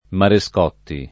Marescotti [ mare S k 0 tti ] cogn.